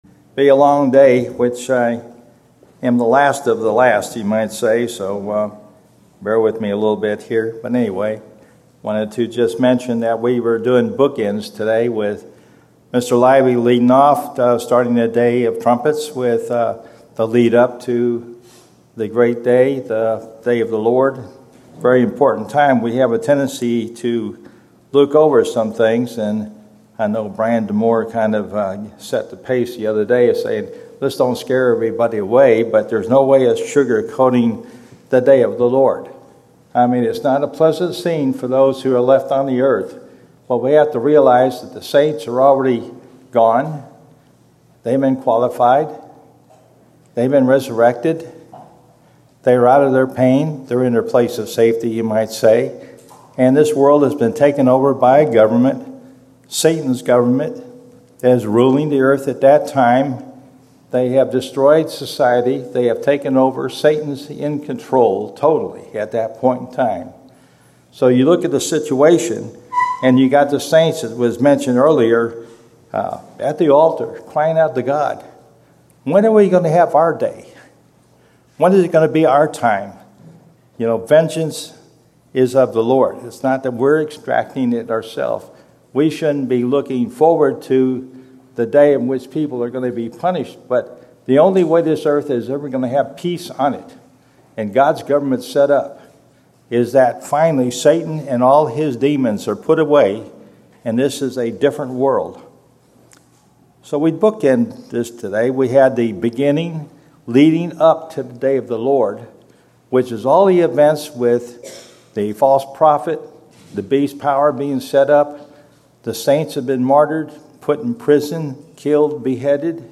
Sermons
Given in Dallas, TX
Feast of Trumpets Studying the bible?